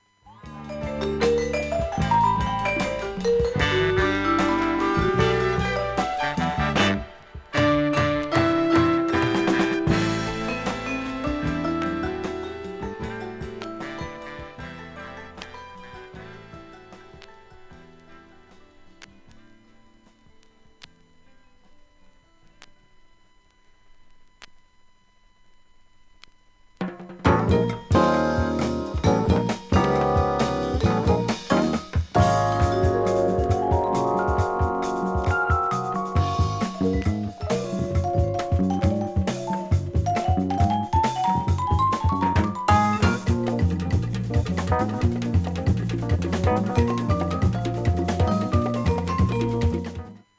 盤面きれいで音質良好全曲試聴済み。